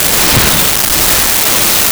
Flyby
flyby.wav